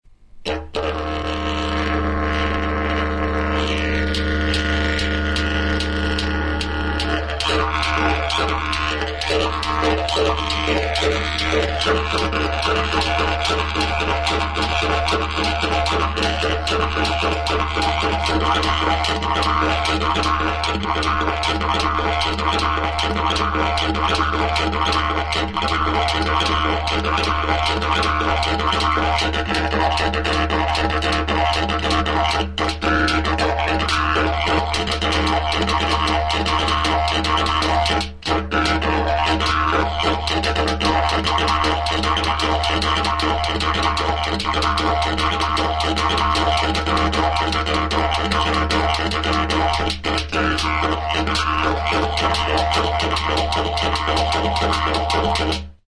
Music instrumentsDIDJERIDU
Aerophones -> Lip vibration (trumpet) -> Natural (with / without holes)
DIDJERIDU